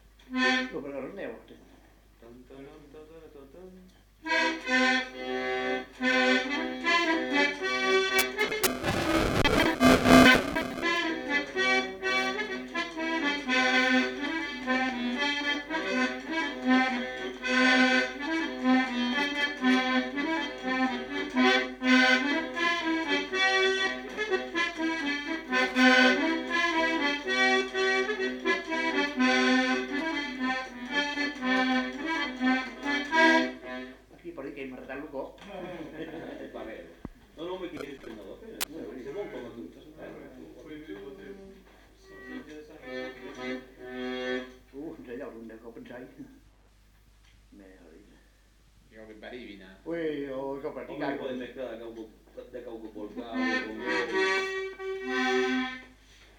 Aire culturelle : Petites-Landes
Lieu : Lencouacq
Genre : morceau instrumental
Instrument de musique : accordéon diatonique
Danse : rondeau
Ecouter-voir : archives sonores en ligne